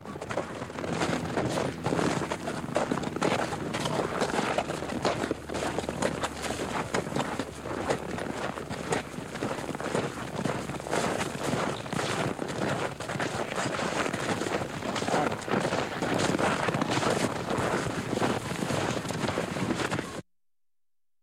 Тихий шаг лошади по снегу